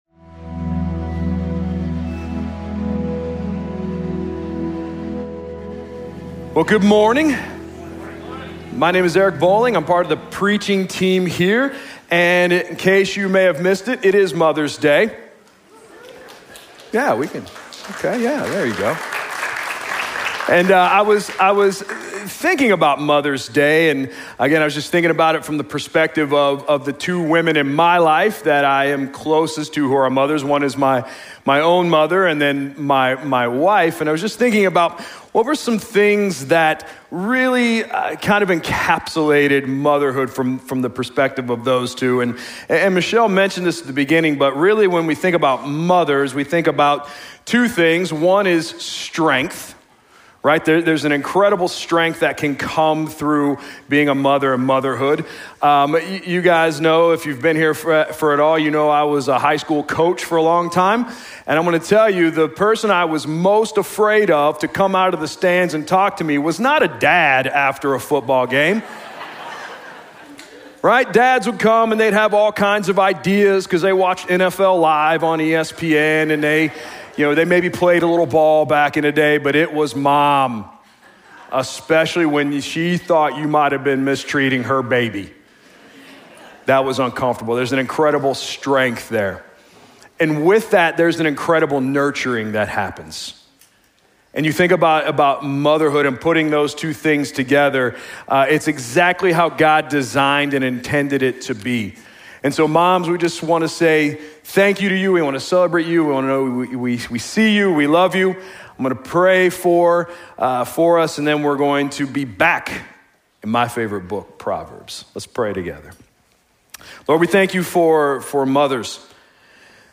Sermons Proverbs